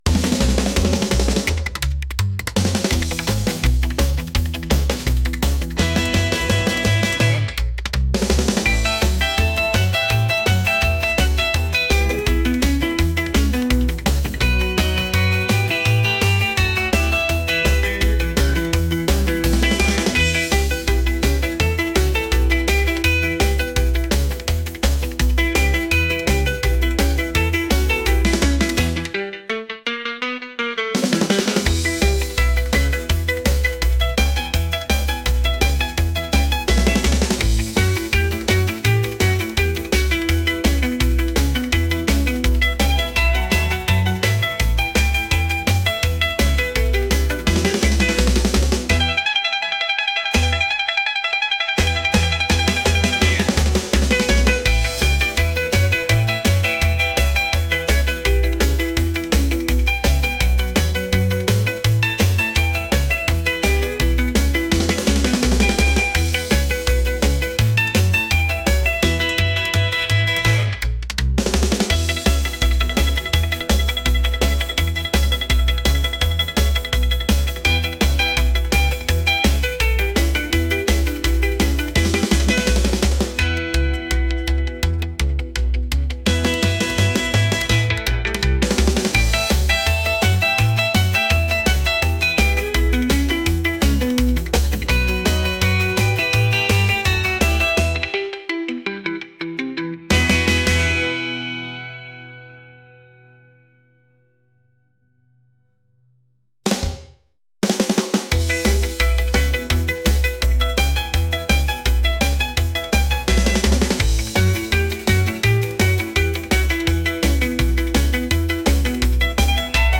energetic | upbeat